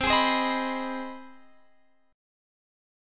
Listen to CmM7#5 strummed